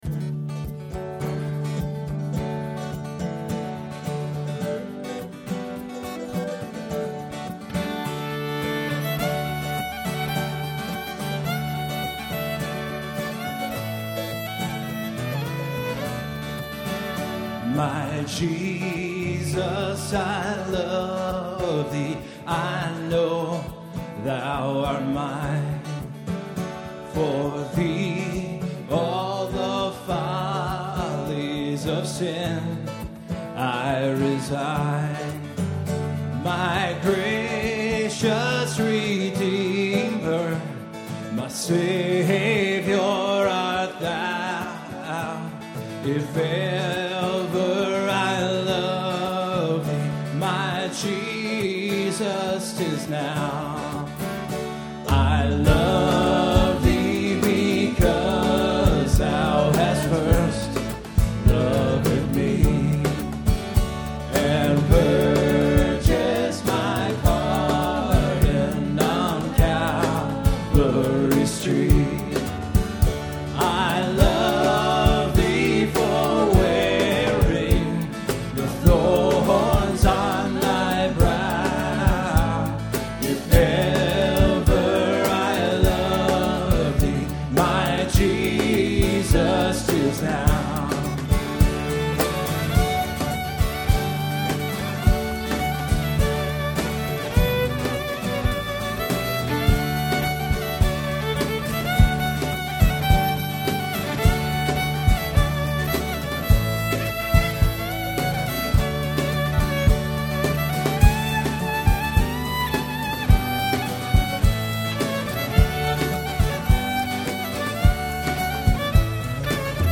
Performed live at Terra Nova - Troy on 6/14/09.